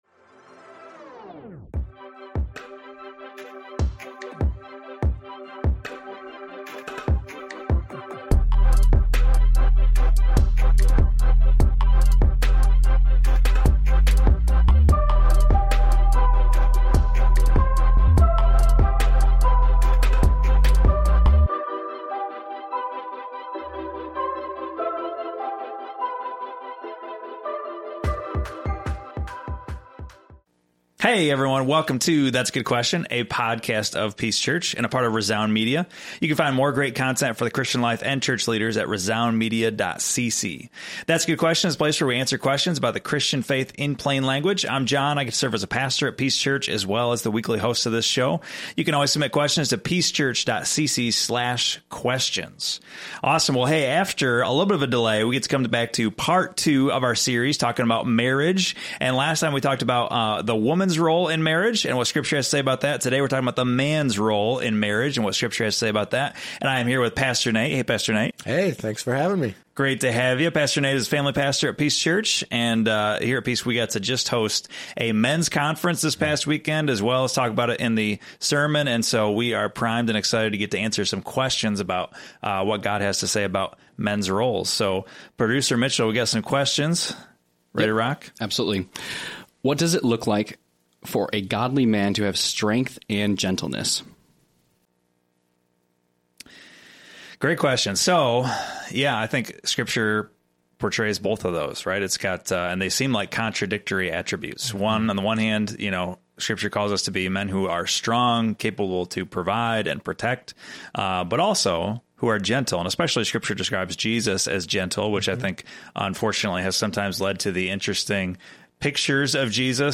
They share tips on how to date for marriage both through a Biblical perspective and their own dating experiences. Tune in for a heartfelt discussion and guidance for Godly dating and marriage.